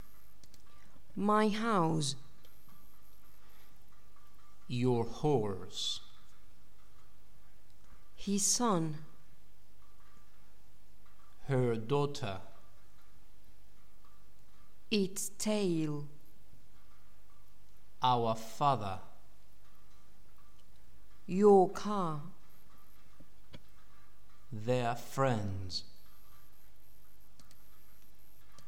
Escucha y repite cada una de ellas prestando especial atención a la pronunciación de los adjetivos posesivos.